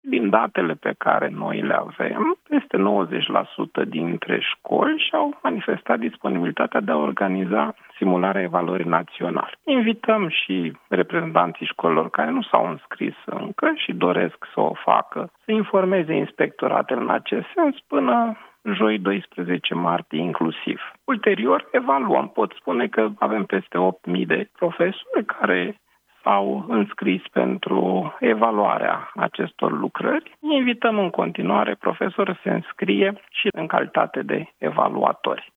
Ministrul Educației, Mihai Dimian: „Invităm în continuare profesorii să se înscrie și în calitate de evaluatori”